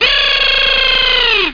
1 channel
00322_Sound_noisemkr.mp3